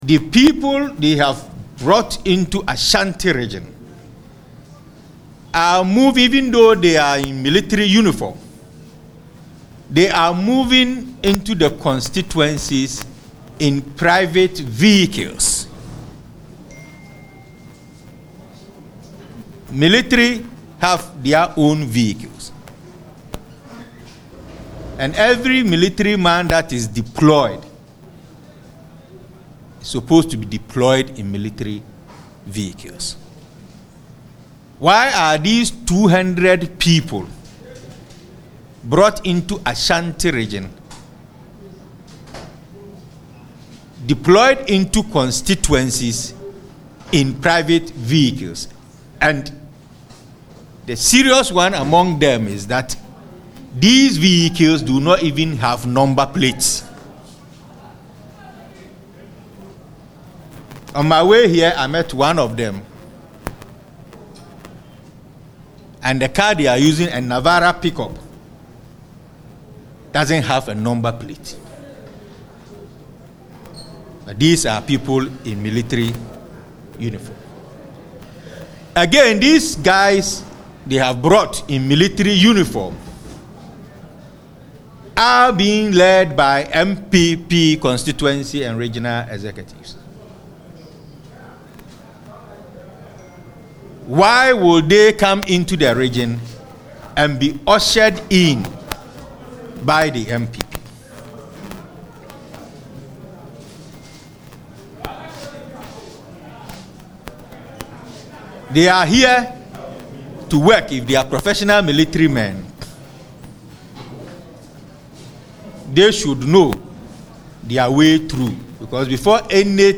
Amid the commotion, one of the individuals kicked over a table, shouting, “This is foolish talk.